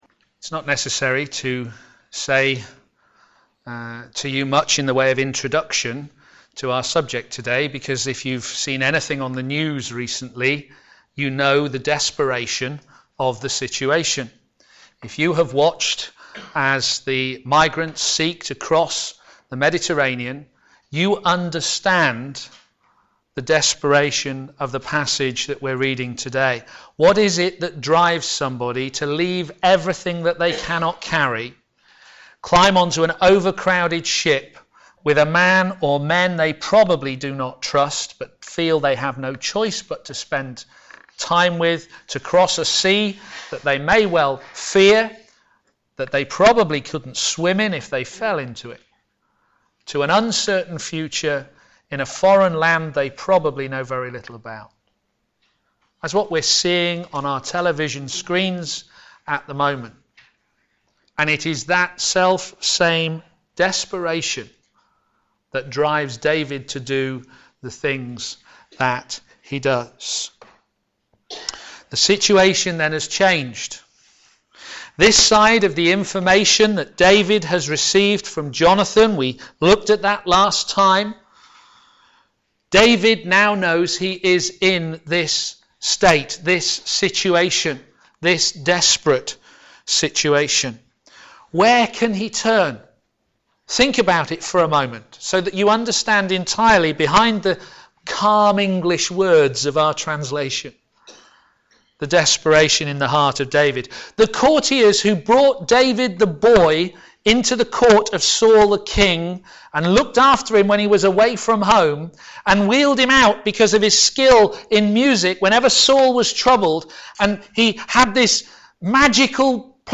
Media for a.m. Service
God's Provision in our Desperation Sermon